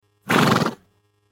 جلوه های صوتی
دانلود صدای اسب 7 از ساعد نیوز با لینک مستقیم و کیفیت بالا